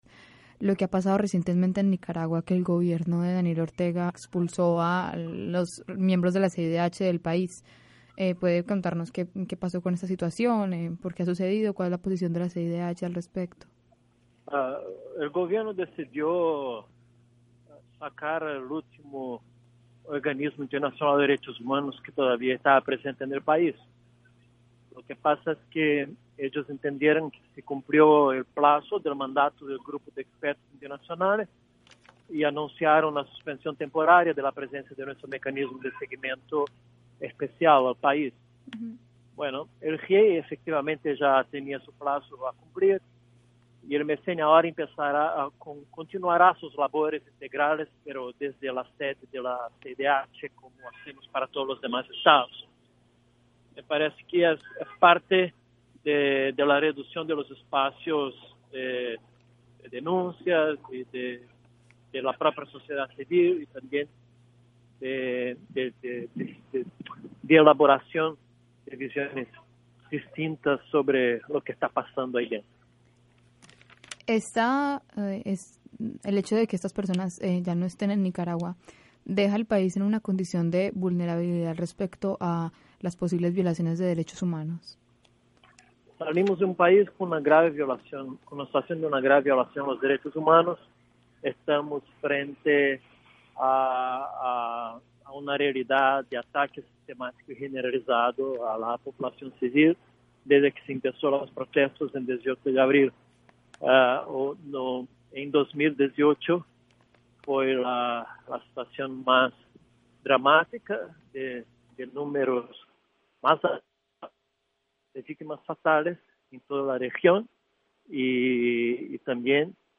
Entrevista a Paulo Abrao, director ejecutivo de la CIDH